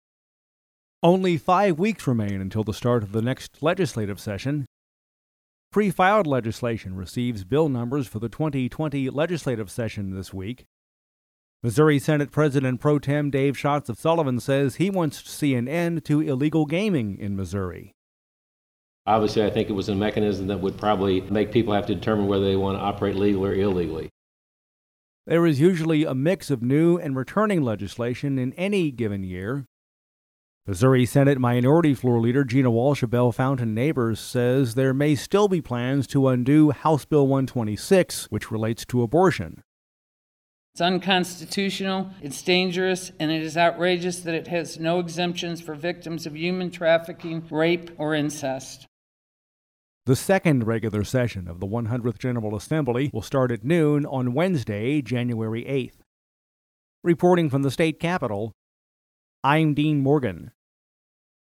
Dec. 3: The first pre-filed measures have received bill numbers for the 2020 legislative session. Many of these proposals closely resemble those that were filed for the 2019 session. We’ve included actualities from Missouri Senate President Pro Tem Dave Schatz, R-Sullivan, and Missouri Senate Minority Floor Leader Gina Walsh, D-Bellefontaine Neighbors
feature report